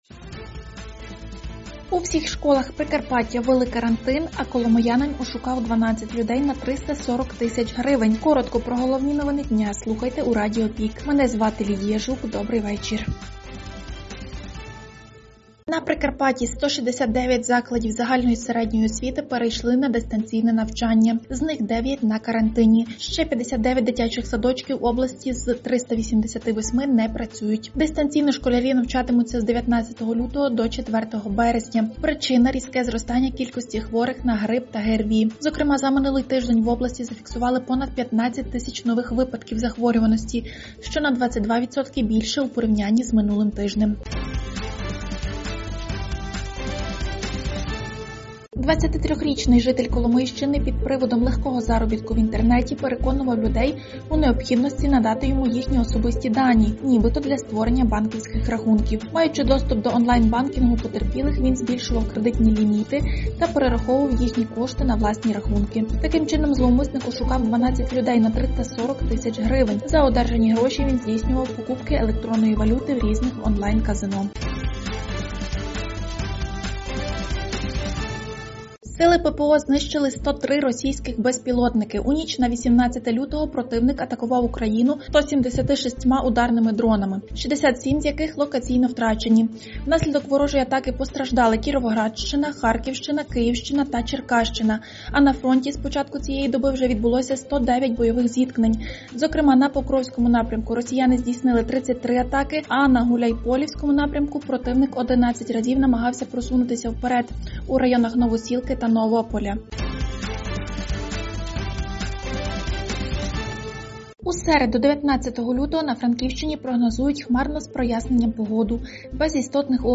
Пропонуємо Вам актуальне за день у радіоформаті.